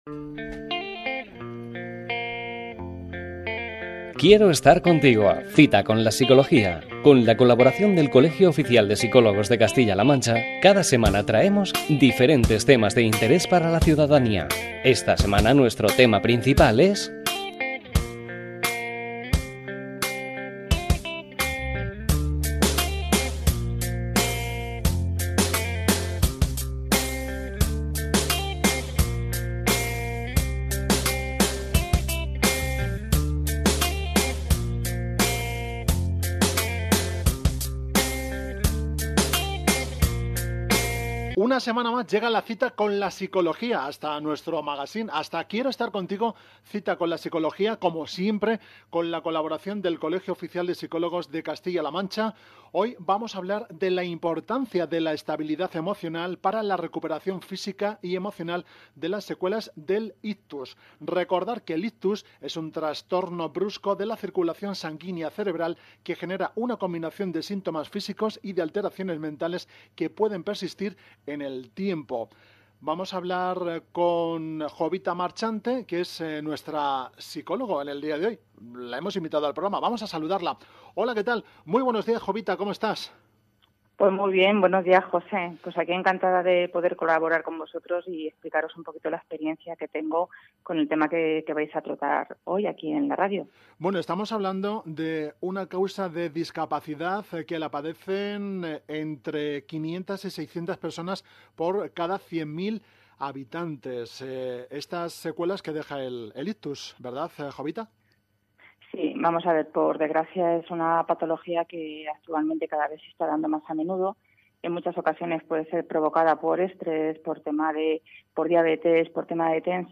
El Colegio Oficial de la Psicología de Castilla-La Mancha colabora con Radio Chinchilla en el magazine “Quiero estar contigo” donde cada semana se abordan temáticas y contenidos psicológicos dentro del espacio “Cita con la Psicología”.